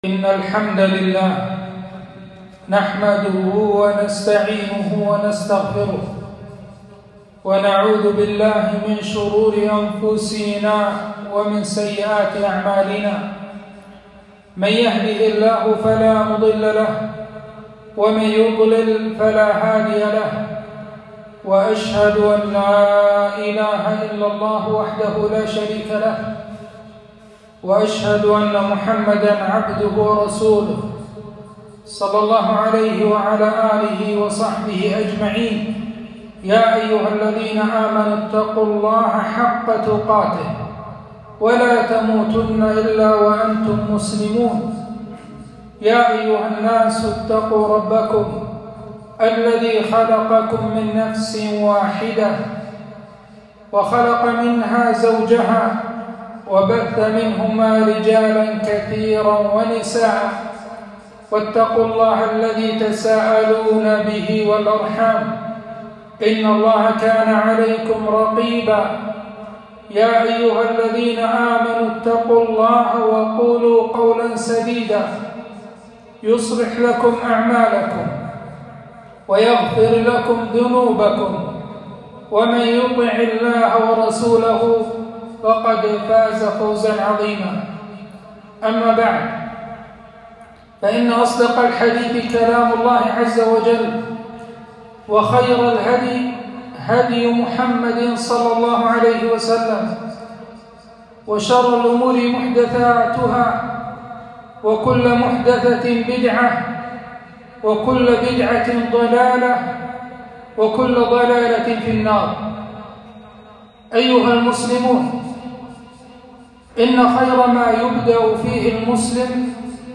خطبة - فضل شهر شعبان